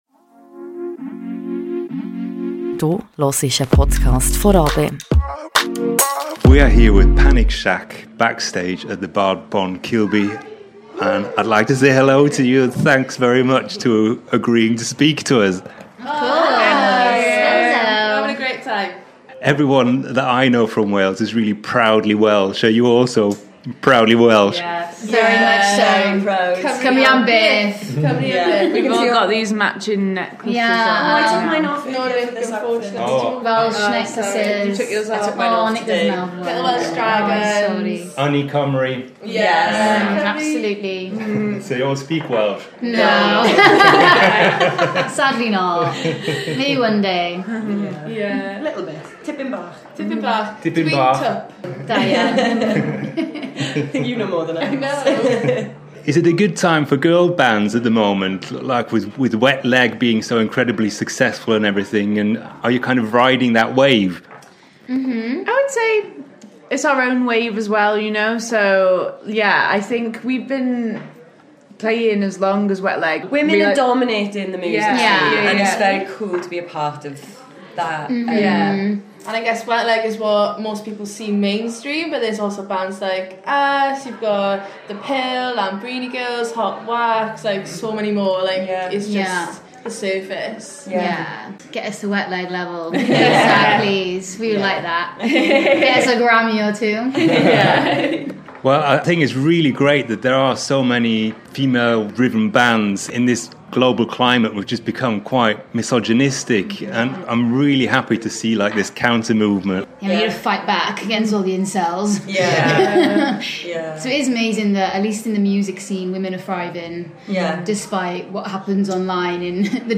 Amplifier-Interview with Panic Shack